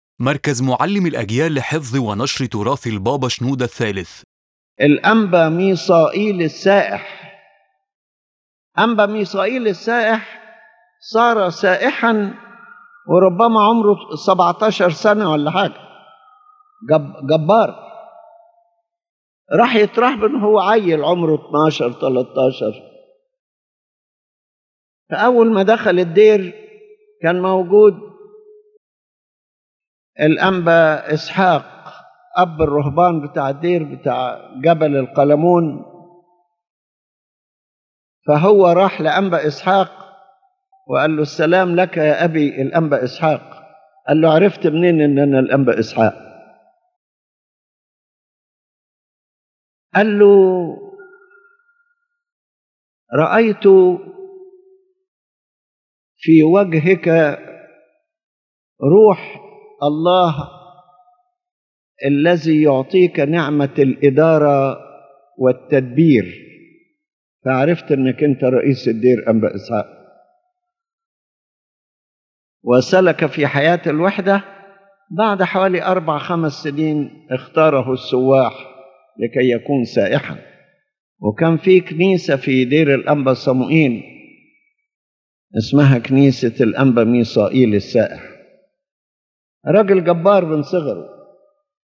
His Holiness Pope Shenouda speaks about the life of Saint Anba Misael the Anchorite, who began his spiritual journey at a very young age — around seventeen — leaving the world to dedicate himself to monastic and anchoritic life.